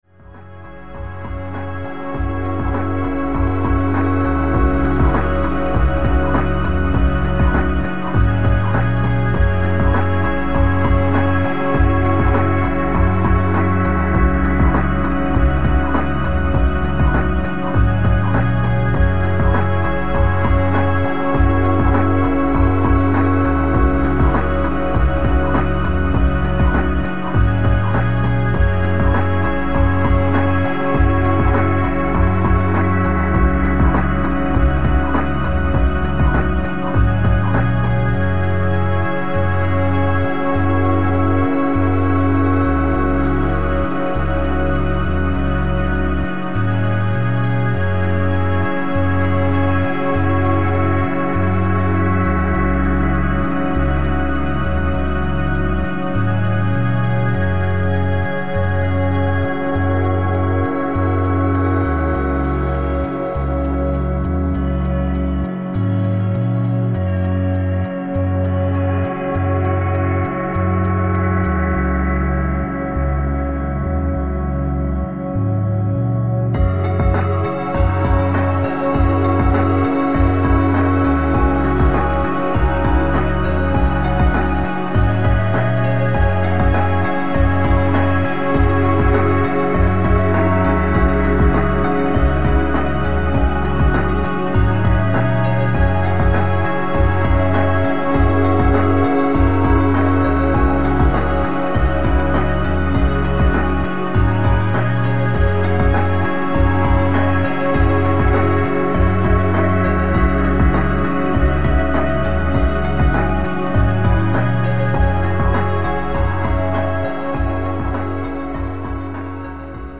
Electro Electronix Techno Dub